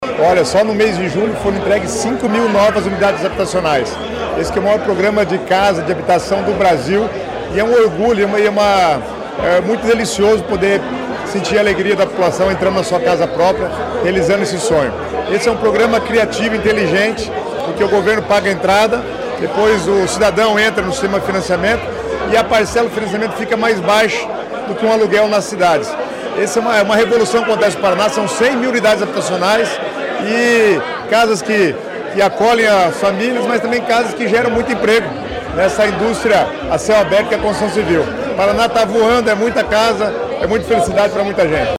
Sonora do secretário das Cidades, Guto Silva, sobre a entrega de residencial com 84 moradias em Paiçandu